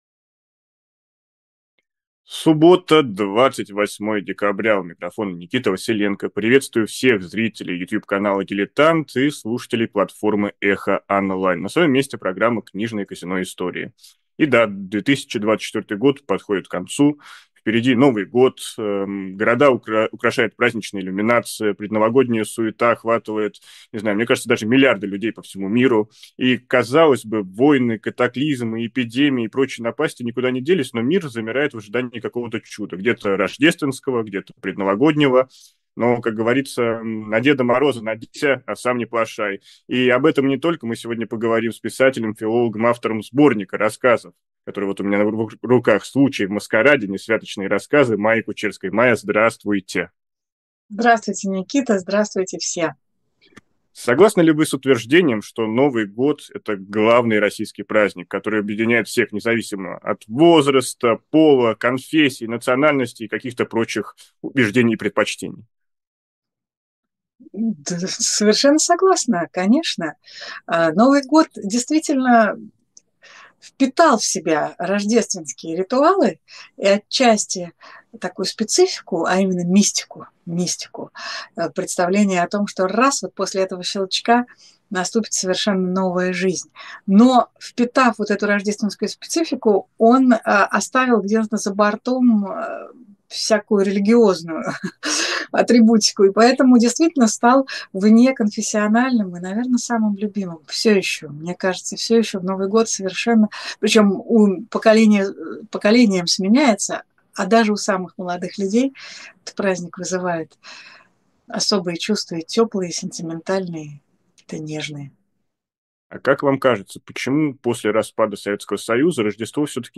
Гость: Майя Кучерская.